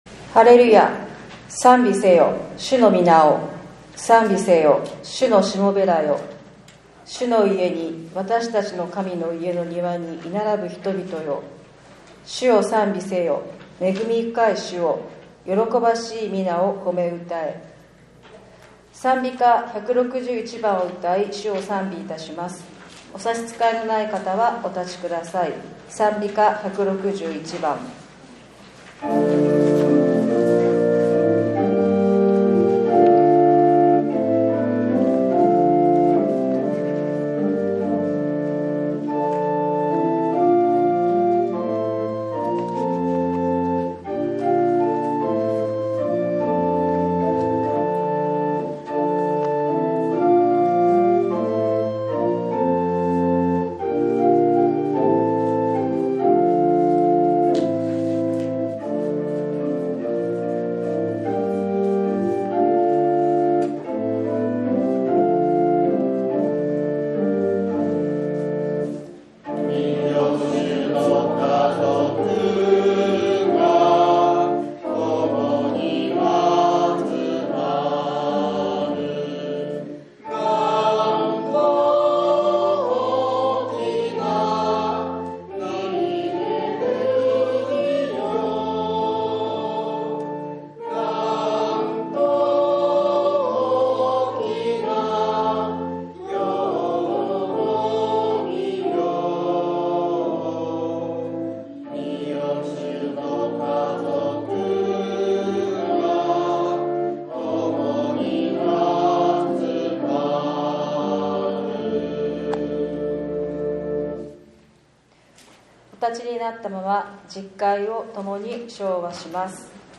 ４月１９日（日）主日礼拝